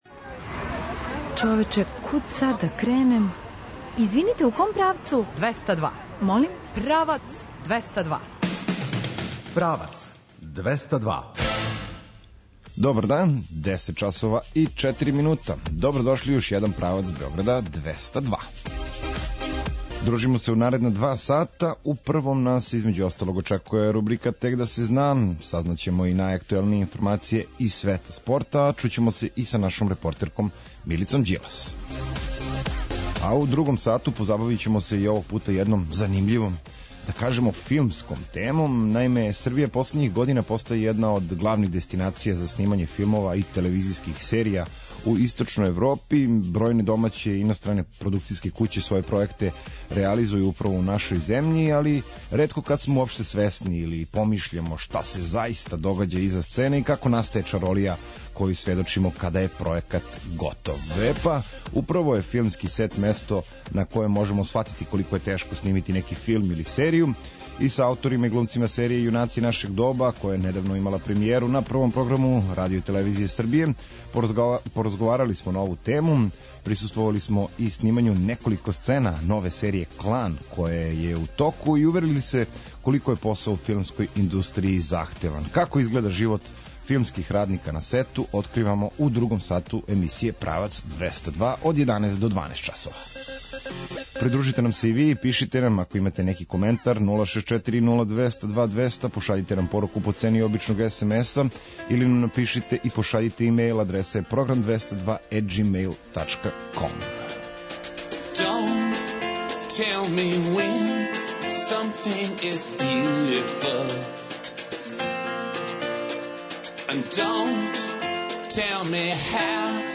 Са ауторима и глумцима серије „Јунаци нашег доба”, која је недавно имала премијеру на првом програму Радио-телевизије Србије, поразговарали смо на ову тему. Присуствовали смо и снимању неколико сцена нове серије „Клан”, које је у току, и уверили се колико је посао у филмској индустрији захтеван.